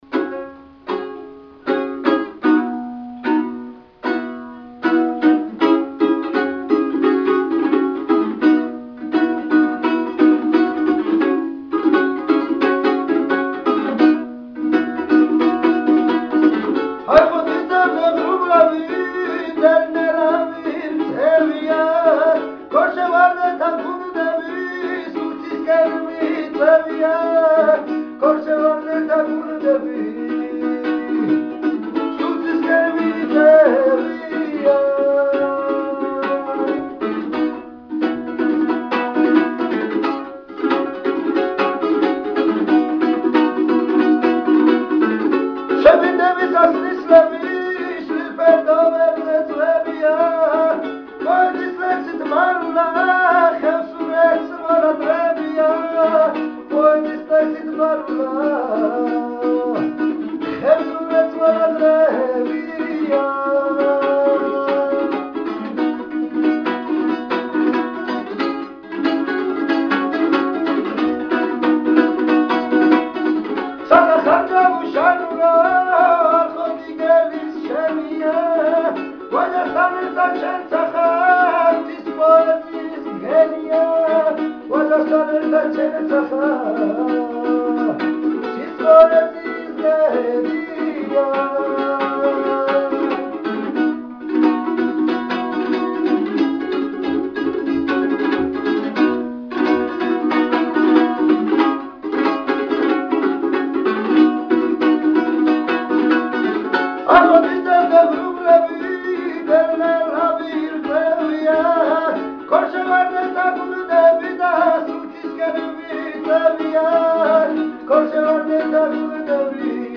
ფანდურა